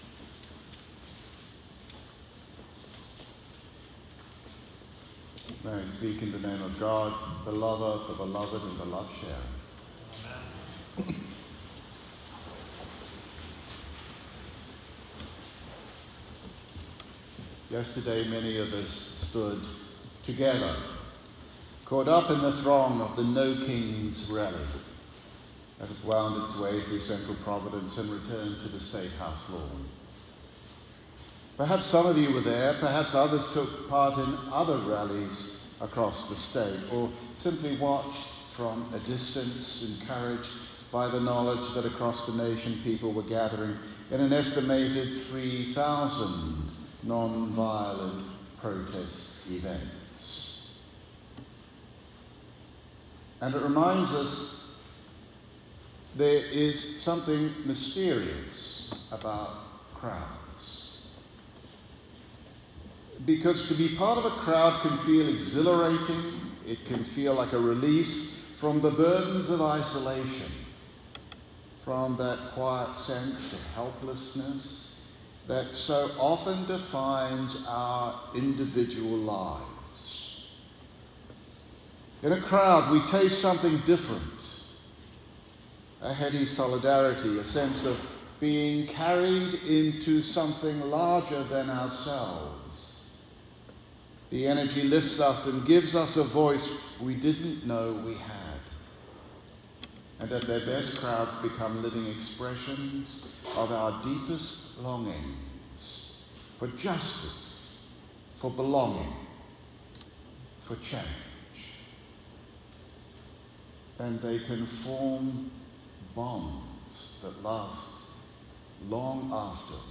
Sermon Audio: The sermon has been streamlined for oral delivery and may differ from the written text below, but the content remains the same.